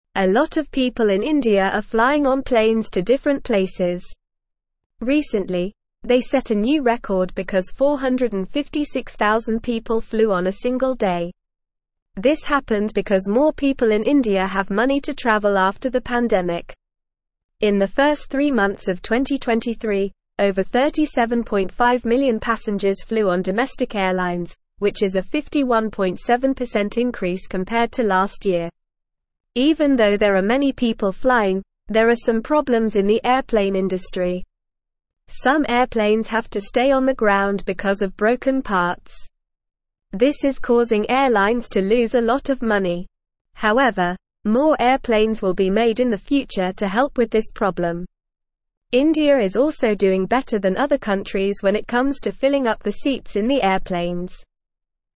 Normal
English-Newsroom-Beginner-NORMAL-Reading-Many-People-Fly-in-India-Despite-Problems.mp3